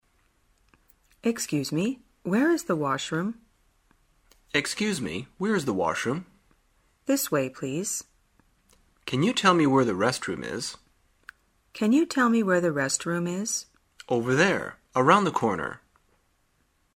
旅游口语情景对话 第312天:如何询问公共卫生间场所